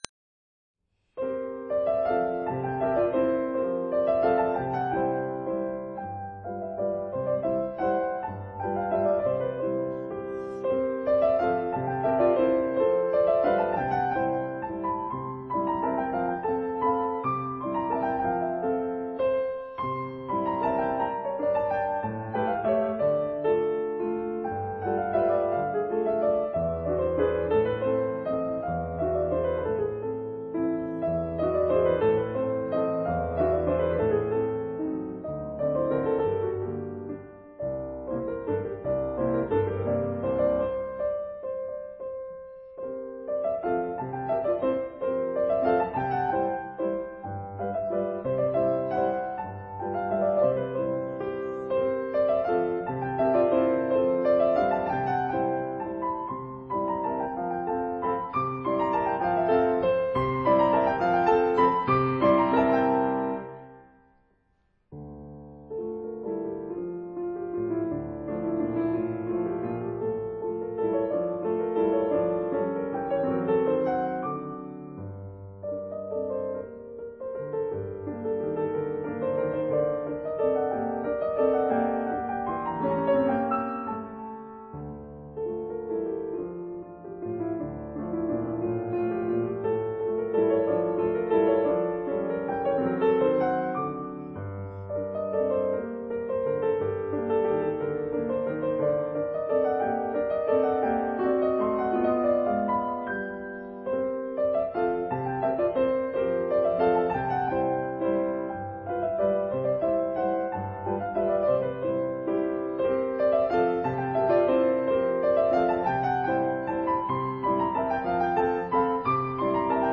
(01) Mazurka in F major, Op. posth
piano